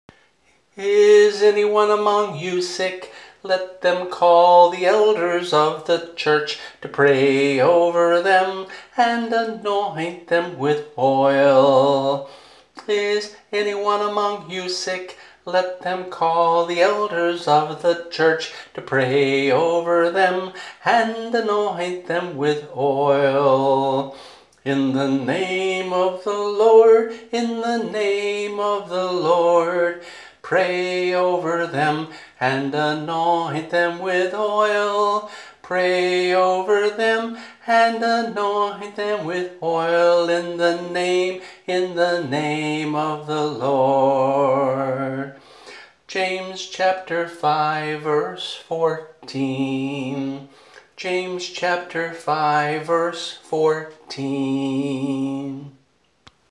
[MP3 - voice only]